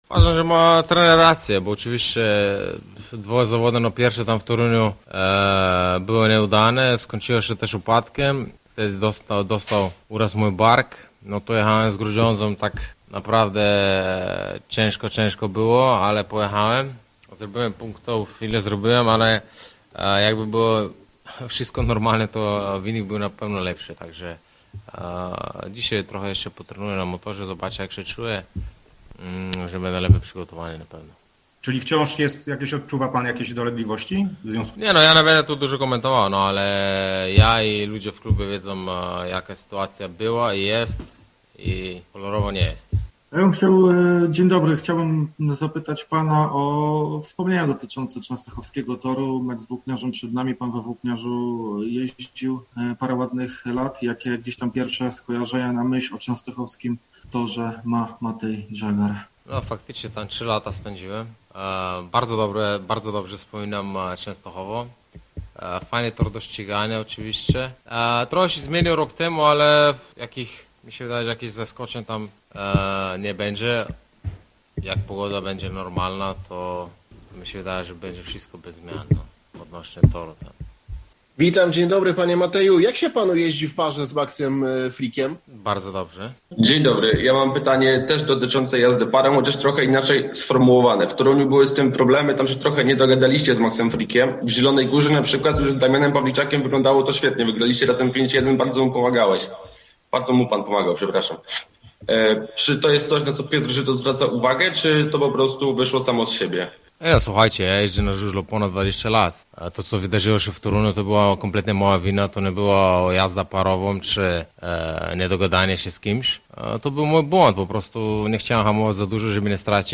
Słoweniec przyznaje, że lubi ścigać się na torze w Częstochowie i chciałby w sobotę odnieść tam zwycięstwo wraz z zielonogórskim zespołem. 38-latek wziął udział w czwartkowej konferencji prasowej w siedzibie zielonogórskiego klubu, która odbyła się w formie on-line i na której dziennikarze zadawali Zagarowi pytania.